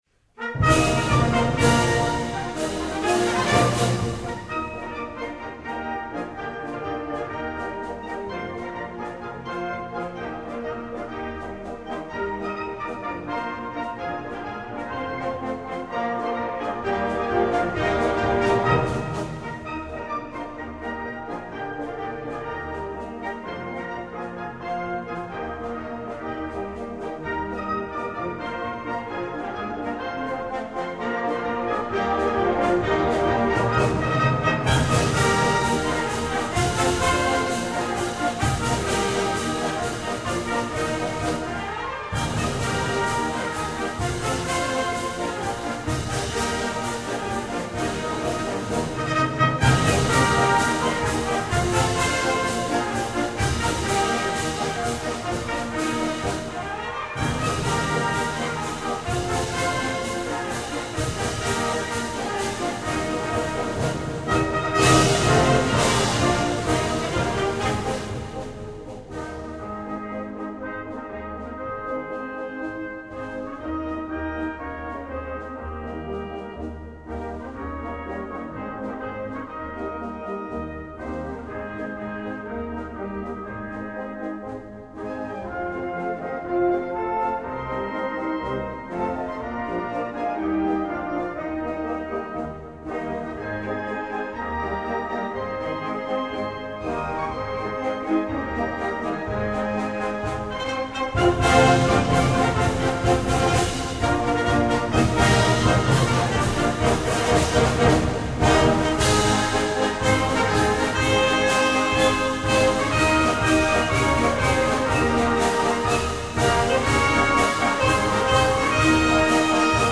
凱旋進行曲.mp3
凱旋進行曲.mp3.ogg